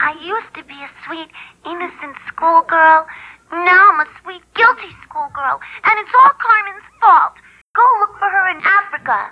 - Edited Patty Larceny's Africa jail call to match her other lines' takes. 2025-12-03 21:02:28 -05:00 875 KiB Raw History